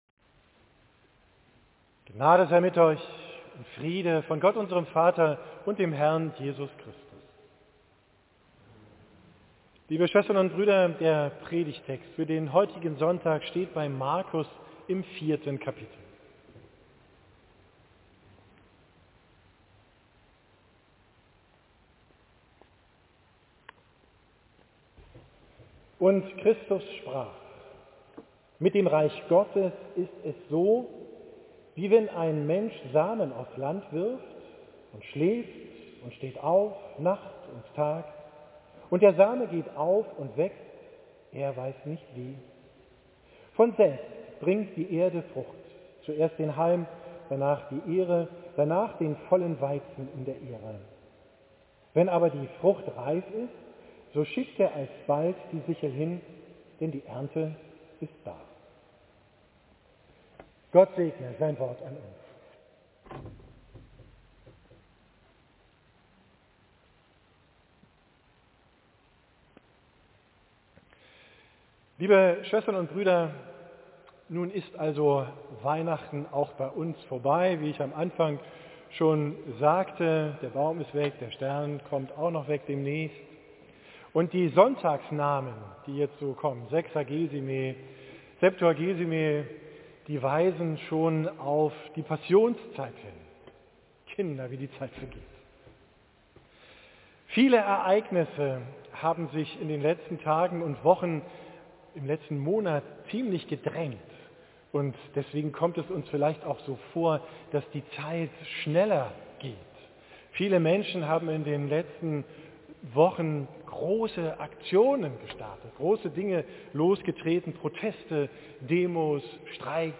Predigt vom Sonntag Sexagesimae, 4.